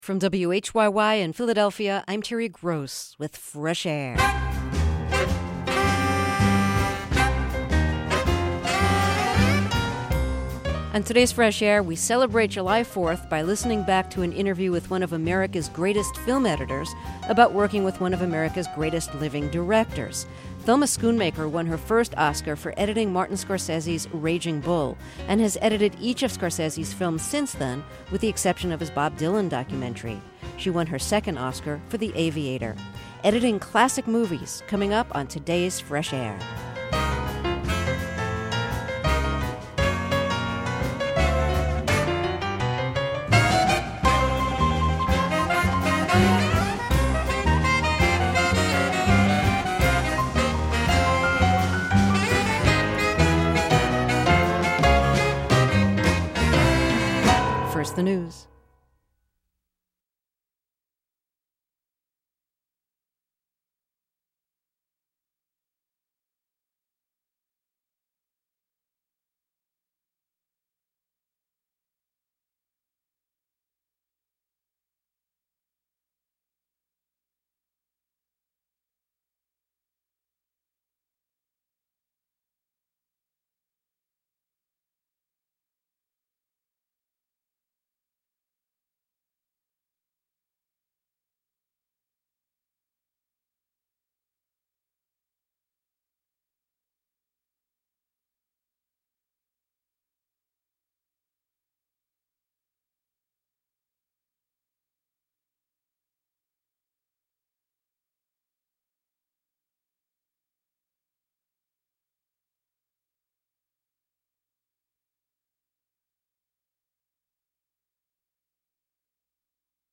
She talks about how film editing has changed over the past 30 years. This interview was originally broadcast May 31, 2005.